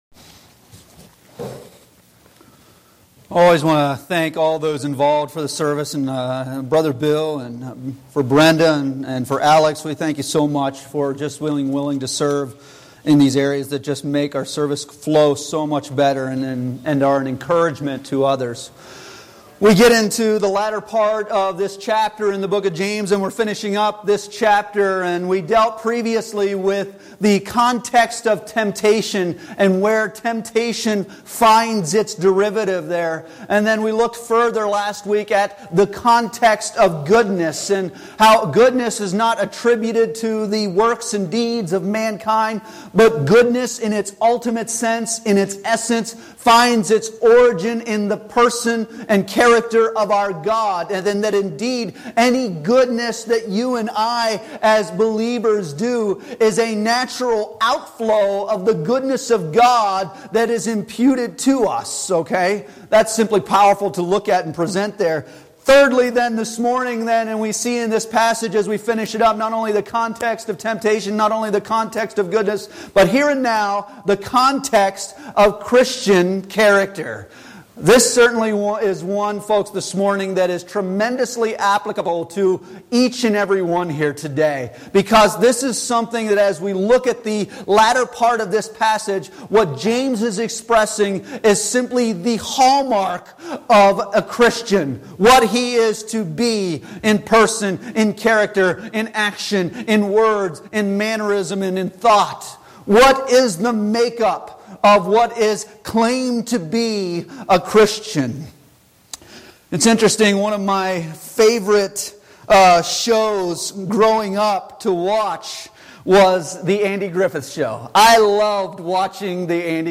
Passage: James 1:19-27 Service Type: Sunday Morning Worship « The Context of Goodness